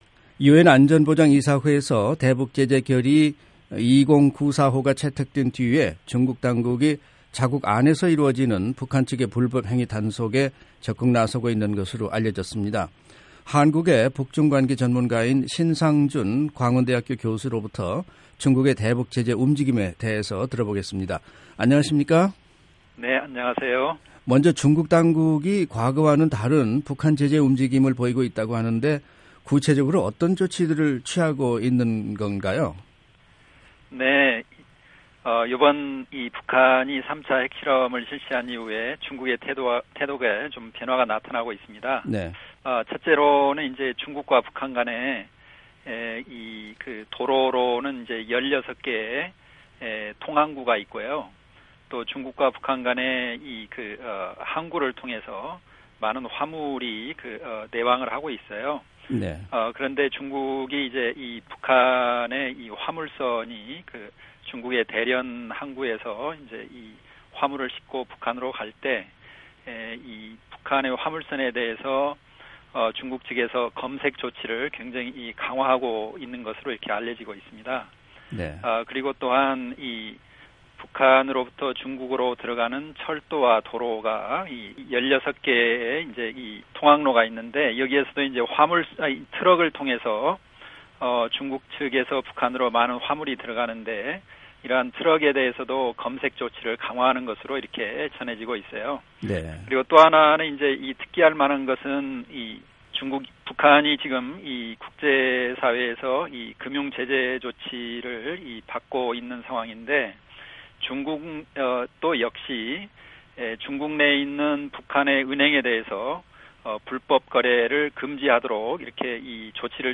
[인터뷰]